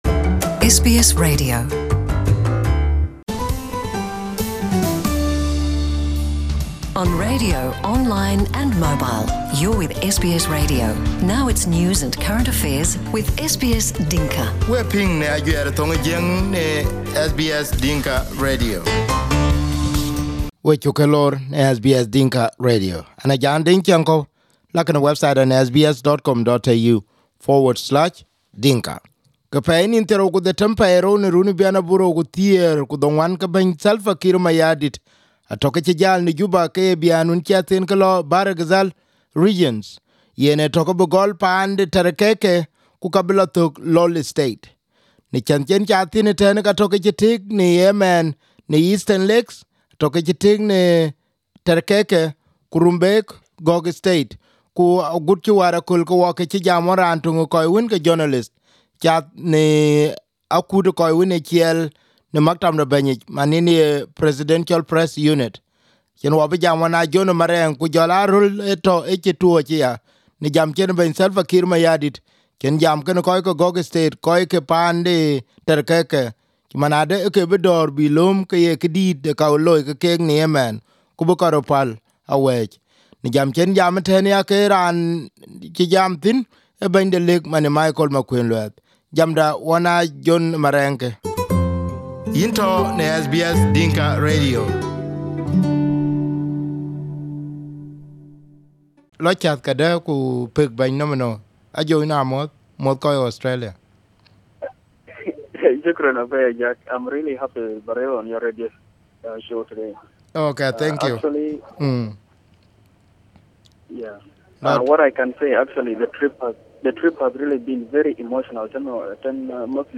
South Sudanese President Salva Kiir is touring Bhar el gazal regions. Ahead of 4 of March, President cut short his trip to receive Ethiopian Prime Minister Abiy Ahmed and Eritrean President Isaias Afwerki who were jointly visiting Juba. SBS interviewed one of the journalists travelling with the President.